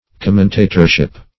Search Result for " commentatorship" : The Collaborative International Dictionary of English v.0.48: Commentatorship \Com"men*ta`tor*ship\, n. The office or occupation of a commentator.